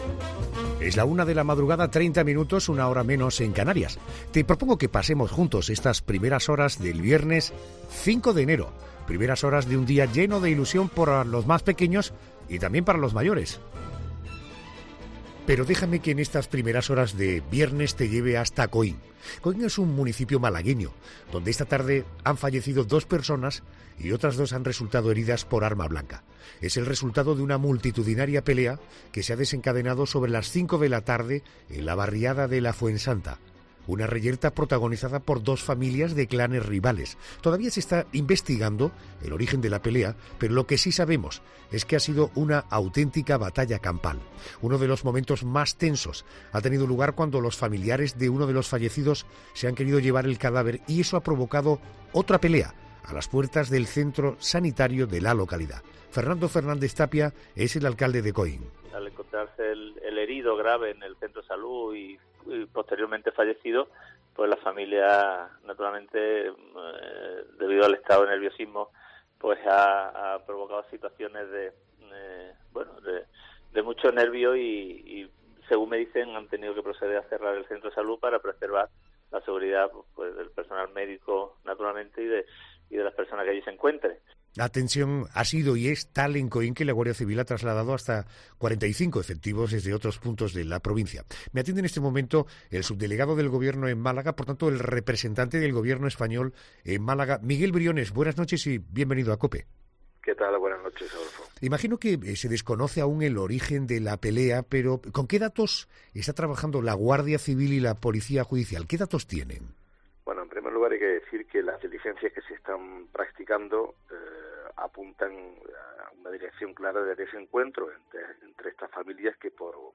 ESCUCHA AQUÍ LA ENTREVISTA AL SUBDELEGADO DEL GOBIERNO EN MÁLAGA EN 'LA NOCHE'